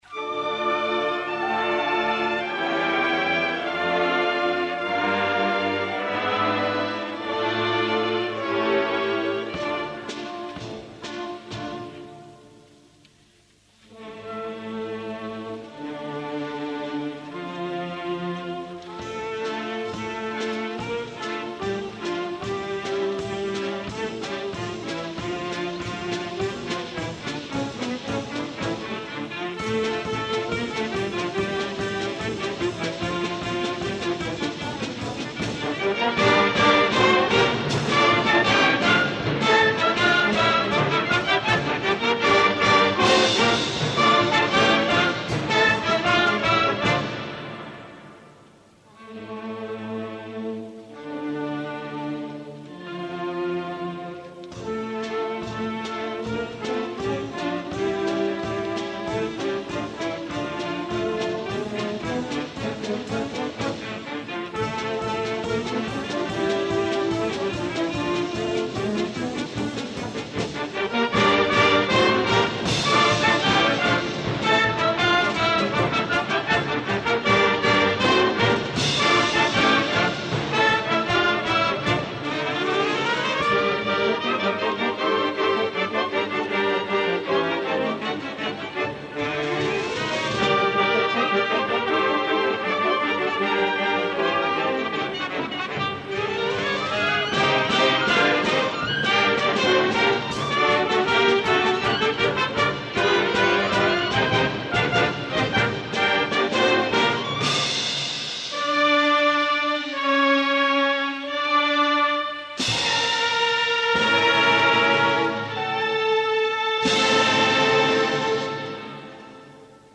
Concert extraordinari 1988. Esglesia parroquial de Porreres Nostra Senyora de la Consolació.